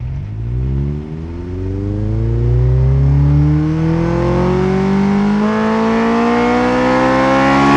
rr3-assets/files/.depot/audio/Vehicles/i4_01/i4_01_accel.wav
i4_01_accel.wav